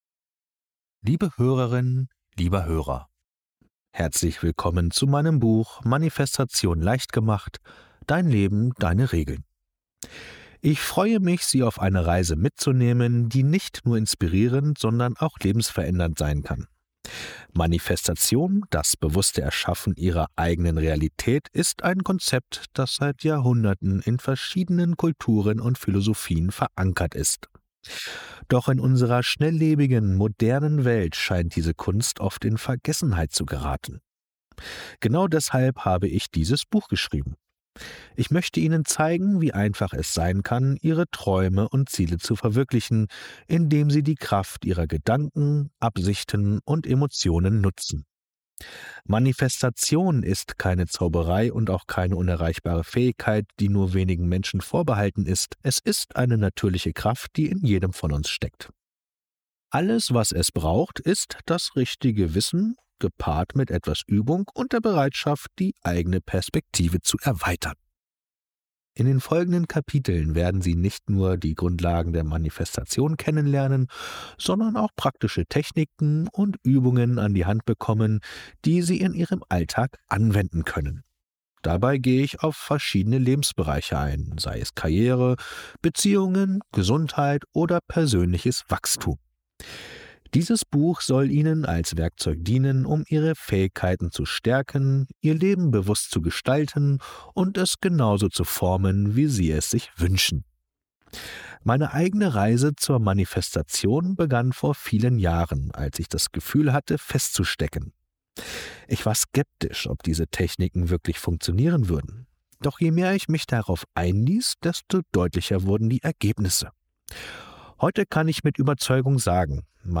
Das Hörbuch erklärt ruhig und verständlich, warum innere Klarheit, emotionale Ausrichtung und konkrete Entscheidungen zusammengehören und weshalb Veränderung immer auch Bereitschaft zur Handlung erfordert.
Besonders positiv fällt auf, dass der Ton sachlich bleibt.
Dadurch wirkt das Hörbuch eher wie ein stiller Begleiter als wie ein Motivationsmonolog.
Manifestation-leicht-gemacht-Hoerprobe.mp3